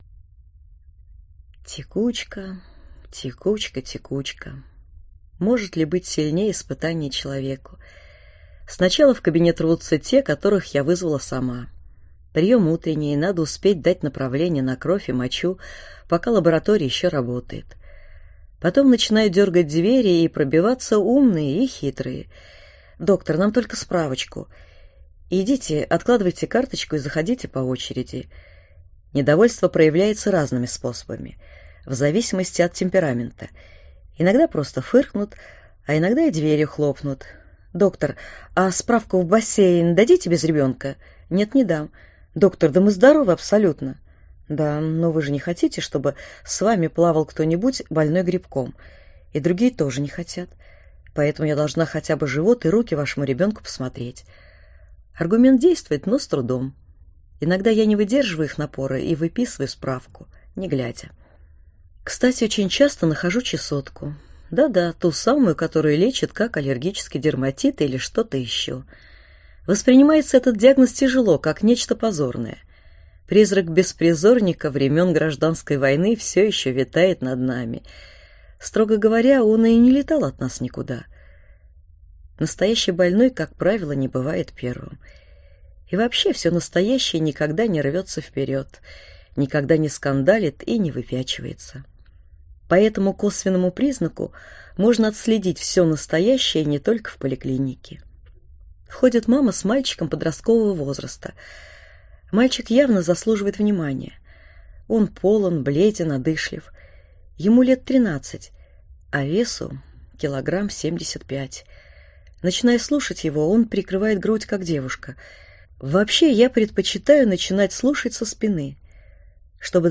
Аудиокнига Помилуй, Господи, наших детей (из практики православного врача) | Библиотека аудиокниг